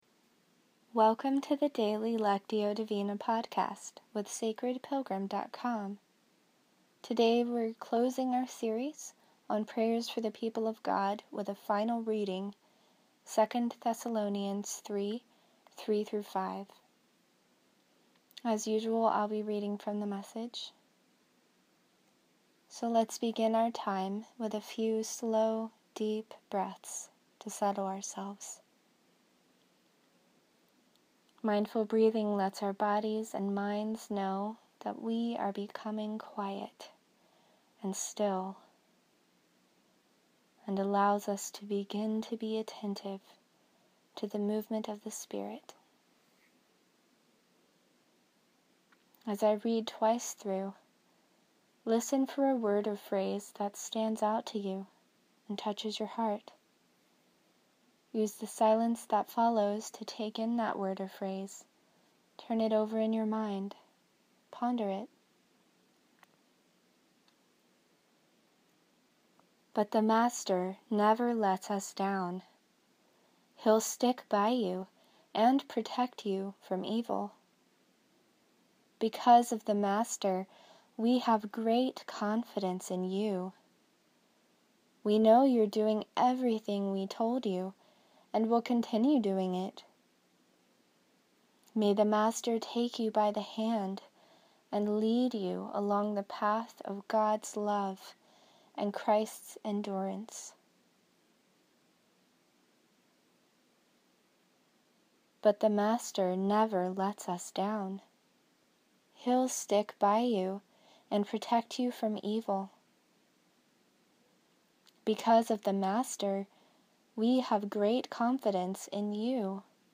In this episode of the guided lectio divina podcast, we’re using one of Paul’s prayers for the Thessalonians (2 Thessalonians 3:3-5).